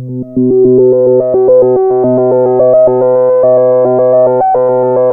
JUP 8 B3 11.wav